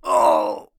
Pain_1.ogg